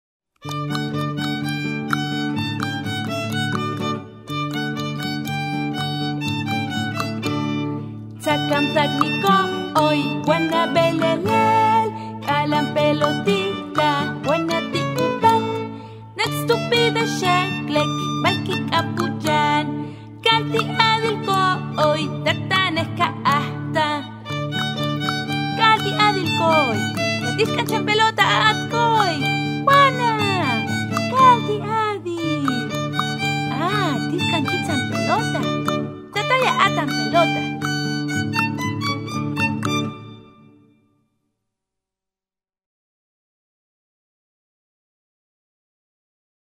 Eine CD mit kleinen Liedern für Kinder in Tének
Cartonalito: Kleine Gitarre
Rabel: Kleine Geige mit drei Saiten